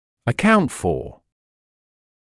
[ə’kaunt fɔː][э’каунт фоː]быть причиной; составлять (о количественных или процентных оценках)